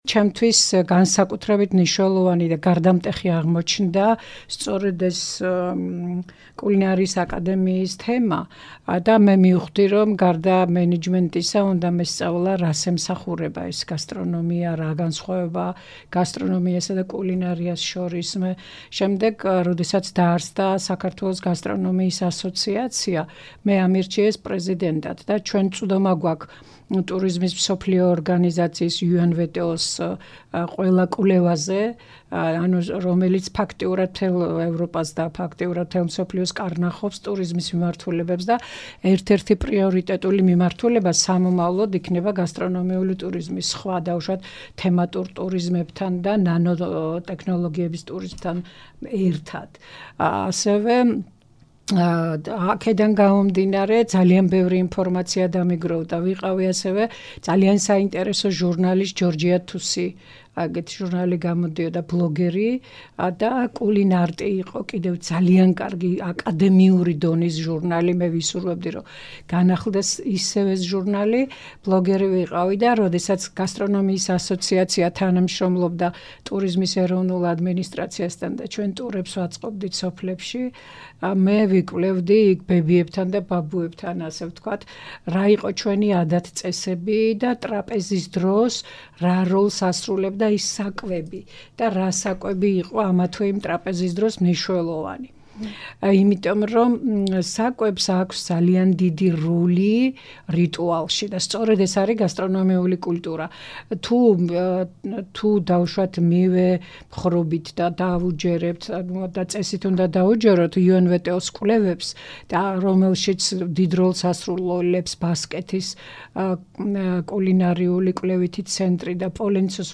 მეფე ერეკლეს ყავა, კანაფის კვერები და ცალფა ღომი - ინტერვიუ გასტრონომიული კულტურის მკვლევართან